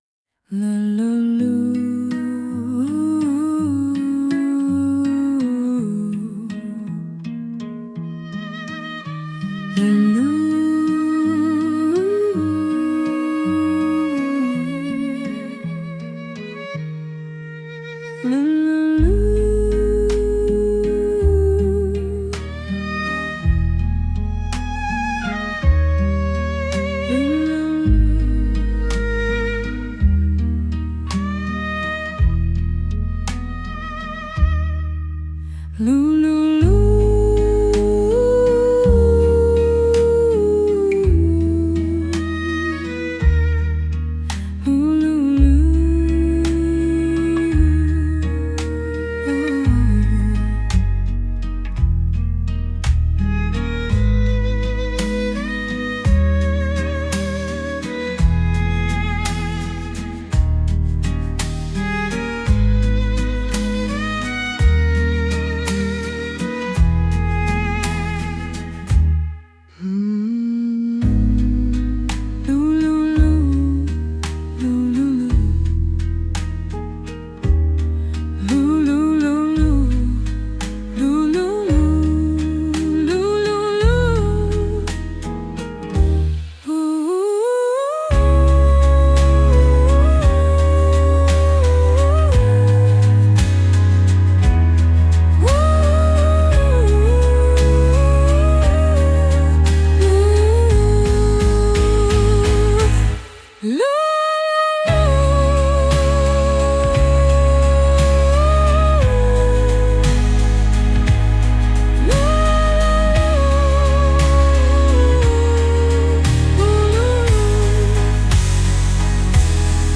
With Vocals / 歌あり